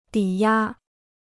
抵押 (dǐ yā) Free Chinese Dictionary